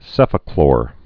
(sĕfə-klôr)